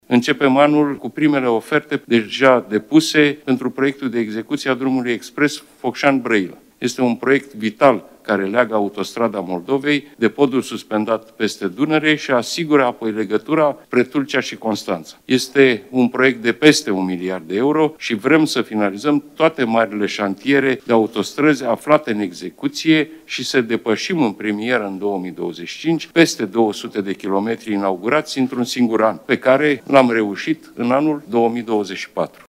Premierul Marcel Ciolacu: Vrem să finalizăm toate marile șantiere de autostrăzi aflate în execuție și să depășim, în premieră în 2025, peste 200 de kilometri inaugurați într-un singur an